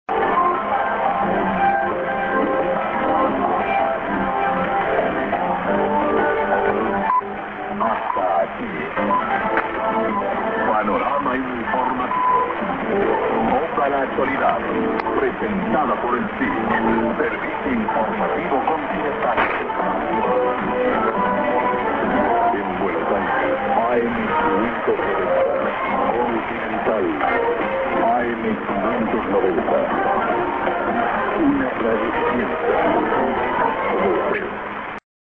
prog->TS->ID(man)-> アナウンスは単に"コンチネンタル"です。